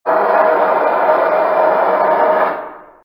EyesScream.MP3